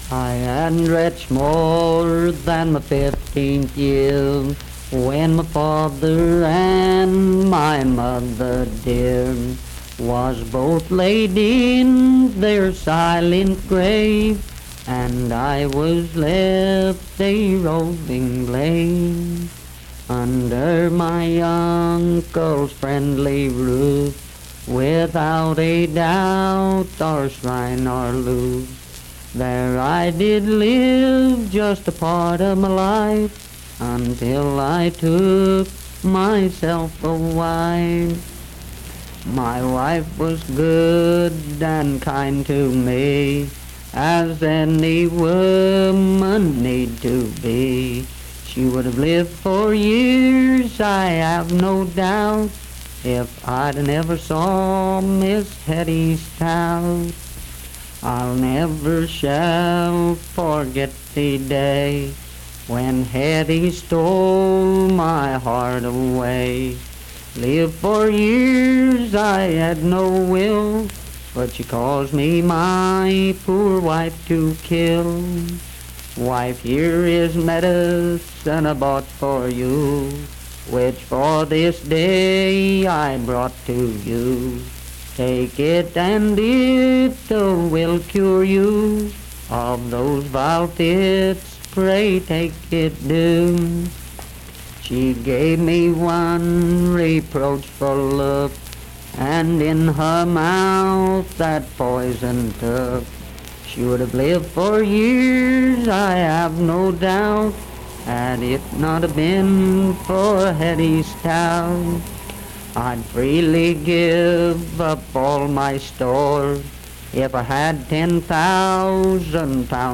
Unaccompanied vocal music performance
Verse-refrain 8 (4).
Voice (sung)